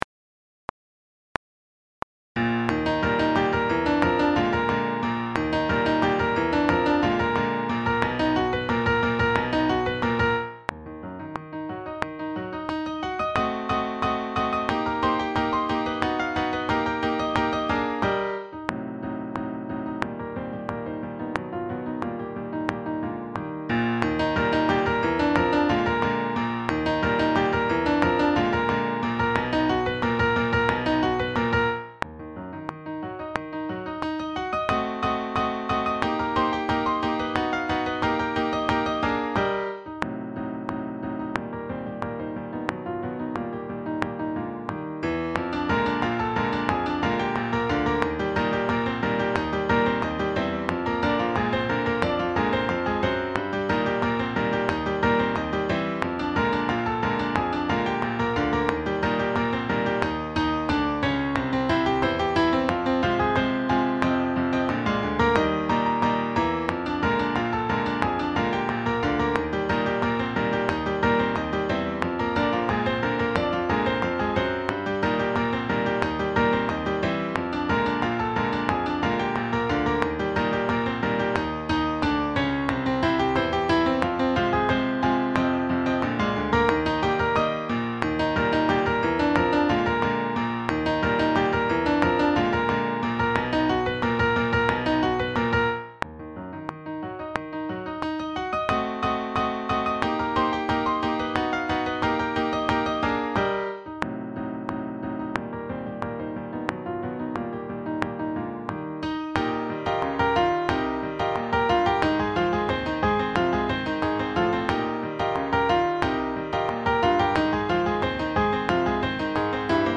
Sax Sextets
sSATBbDuration:
Backing track